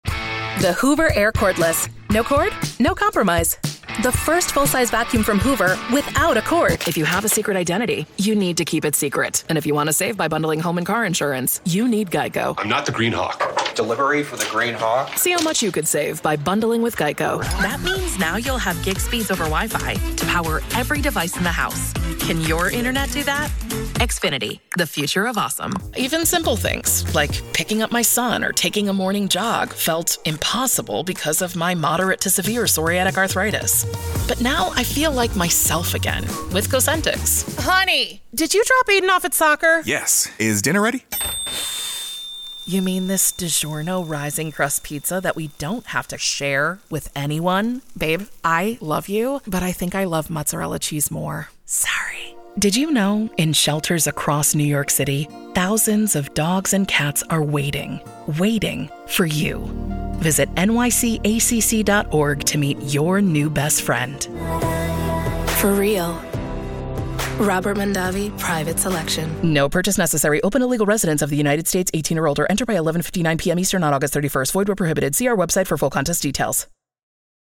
New York : Voiceover : Narration : Women